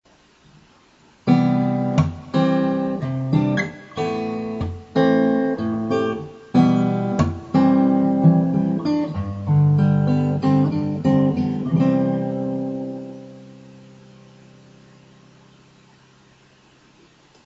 4フィンガーメロディアスプレイ
4フィンガーのフレーズです。